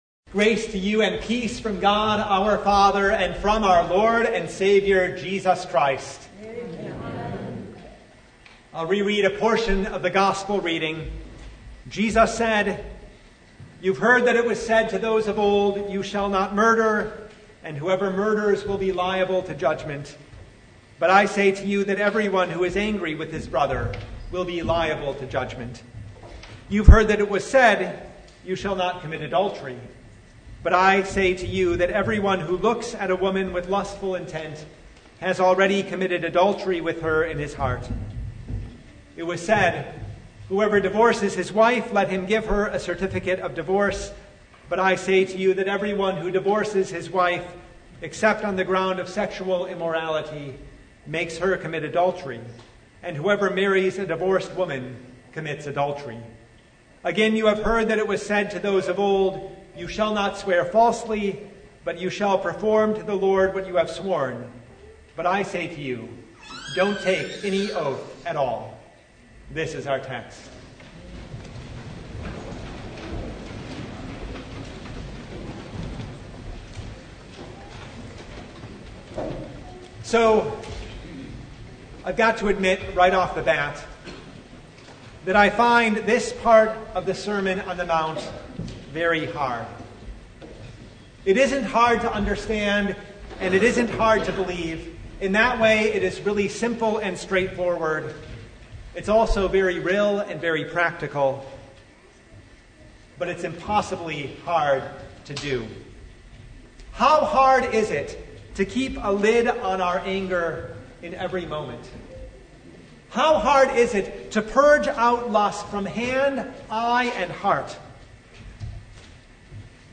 Matthew 5:21-37 Service Type: Sunday Jesus has anger